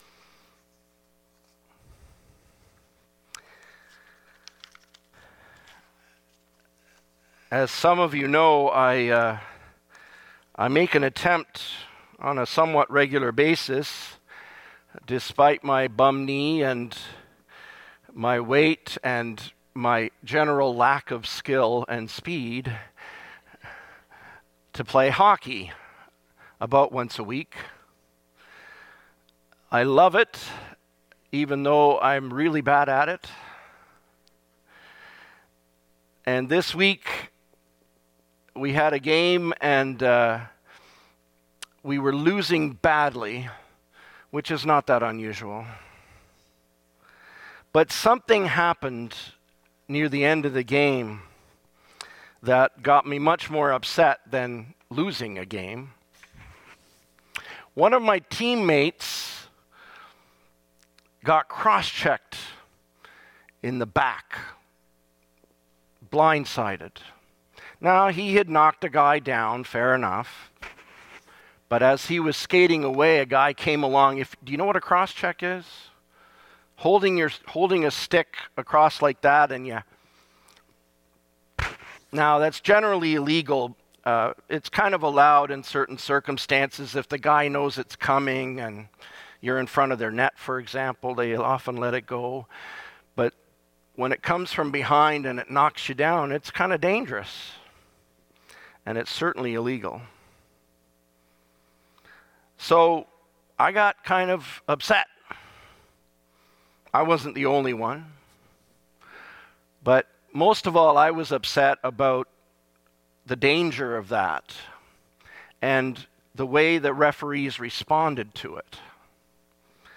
Sermons | Gilmore Park United Church